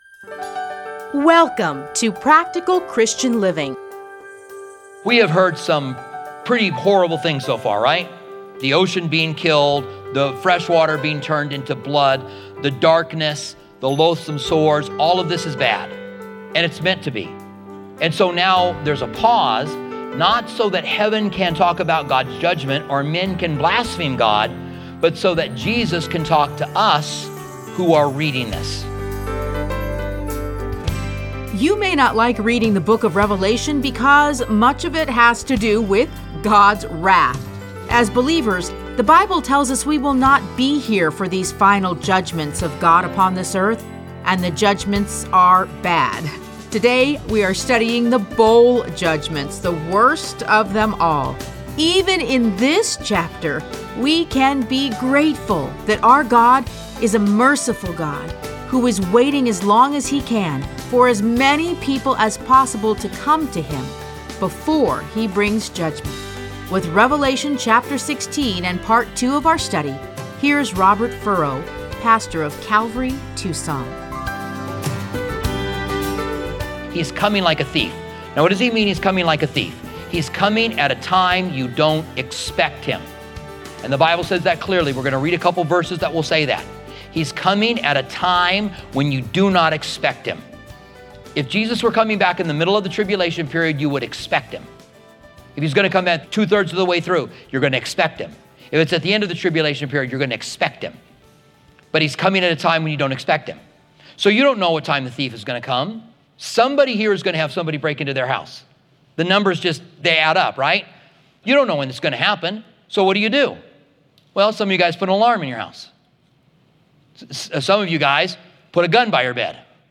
Listen to a teaching from Revelation 16:1-21.